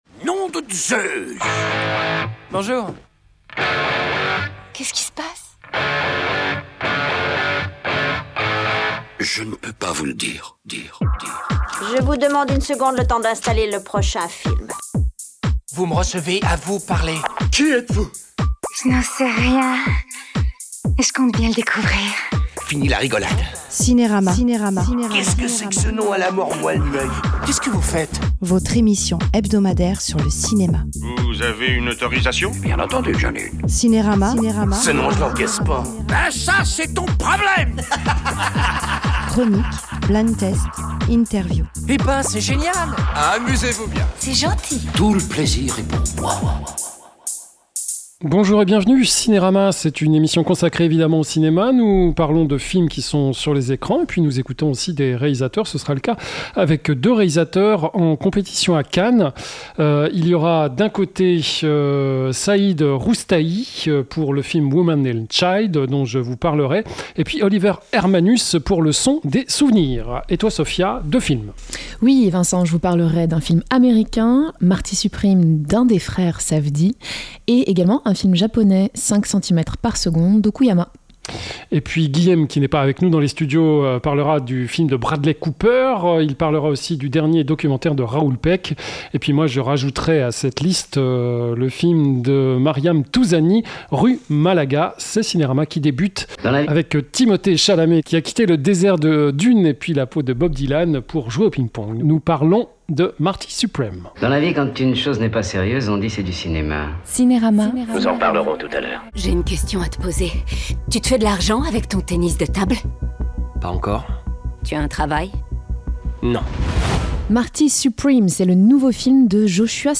LES INTERVIEWSNous allons écouter deux réalisateurs présents en compétition au dernier festival de Cannes. D’un côté Oliver Hermanus pour Le Son des souvenirs et Saeed Roustaee pour Woman And Child.